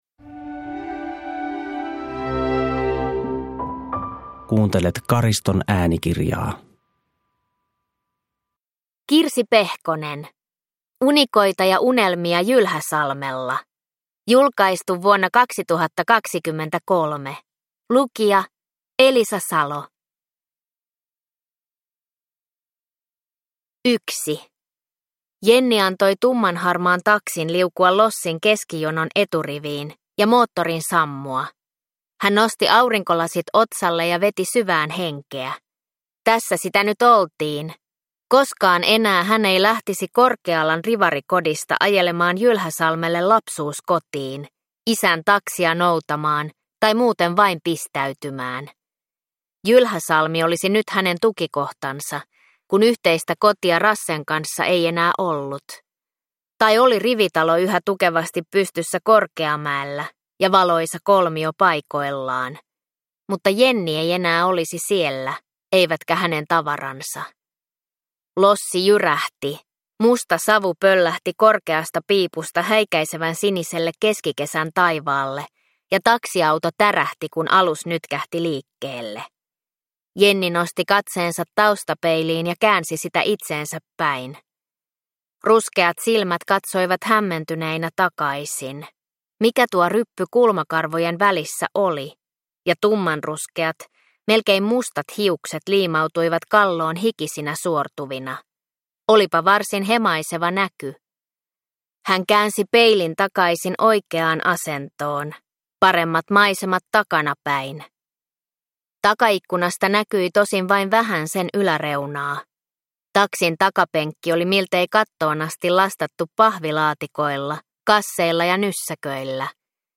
Unikoita ja unelmia Jylhäsalmella (ljudbok) av Kirsi Pehkonen